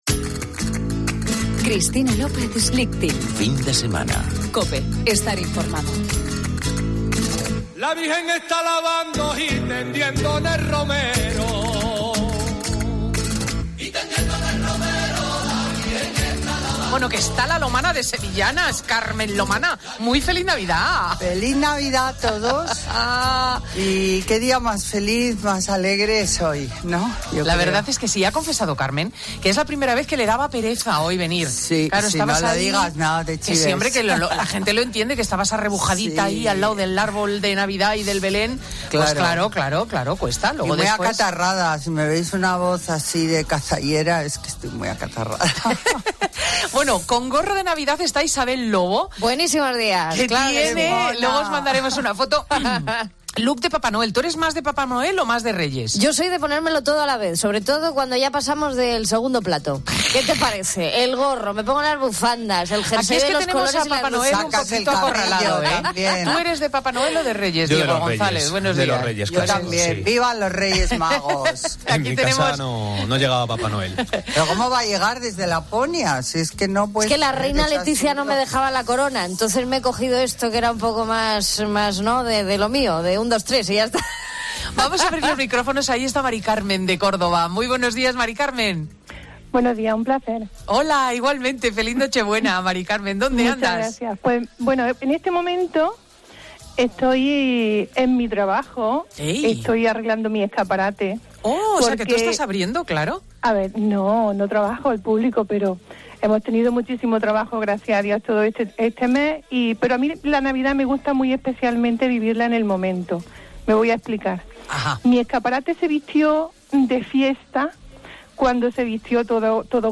Escucha a Carmen Lomana atendiendo a los oyentes y las preguntas que le envían desde 'Fin de semana' de Cristina López Schlichting